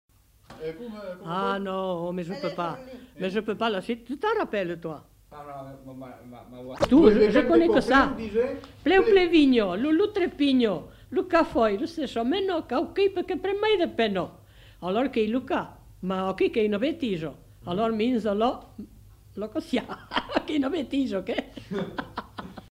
Formulette
Aire culturelle : Périgord
Lieu : Saint-Amand-de-Vergt
Genre : forme brève
Type de voix : voix de femme
Production du son : récité